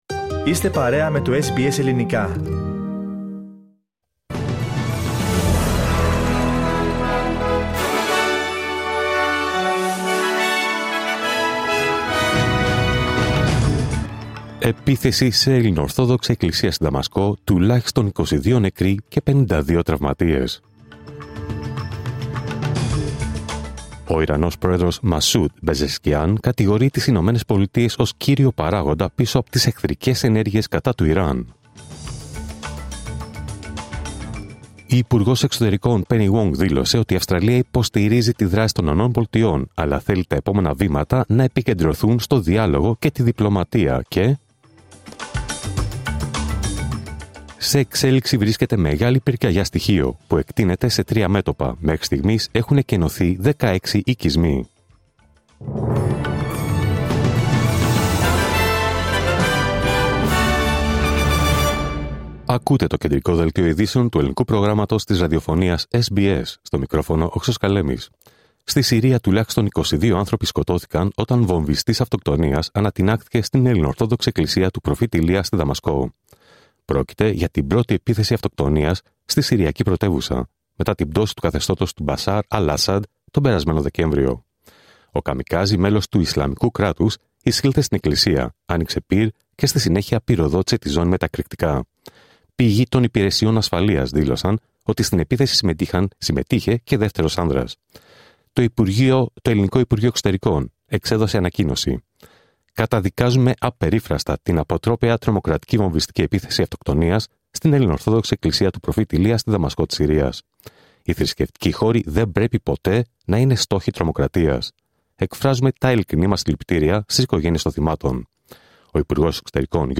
Δελτίο Ειδήσεων Δευτέρα 23 Ιουνίου 2025